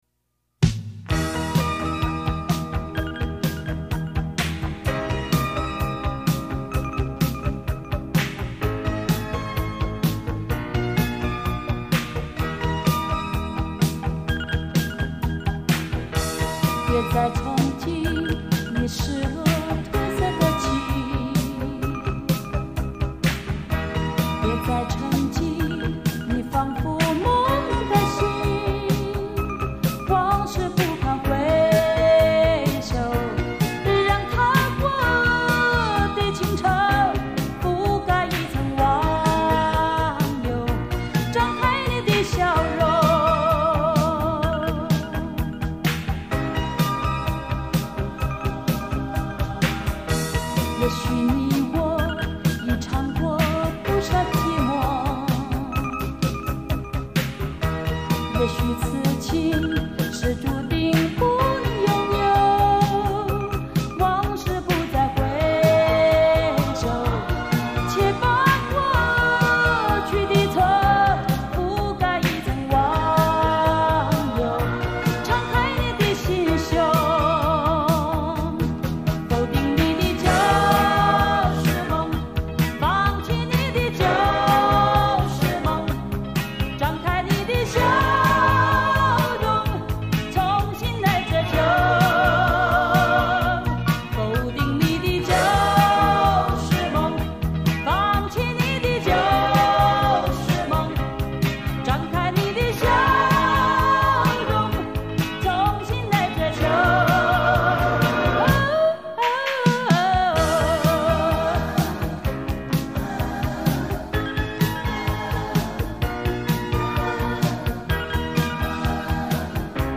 很欢快的一张专辑！！！